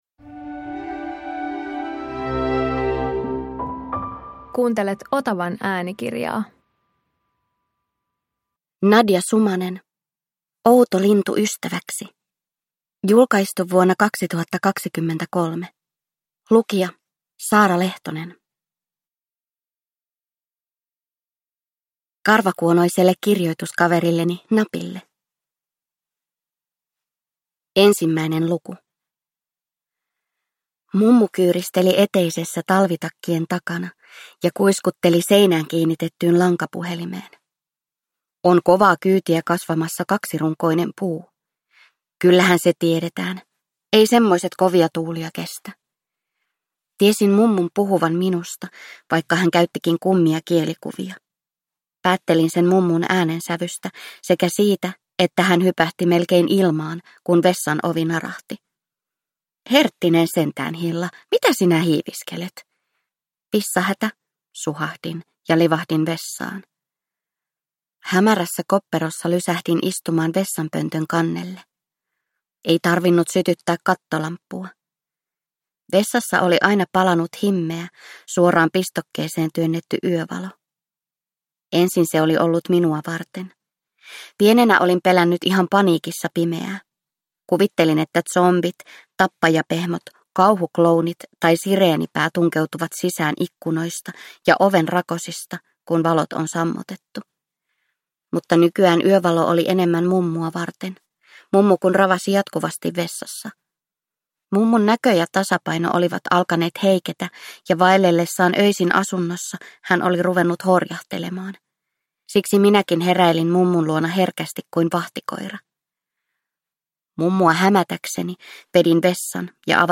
Outo lintu ystäväksi – Ljudbok – Laddas ner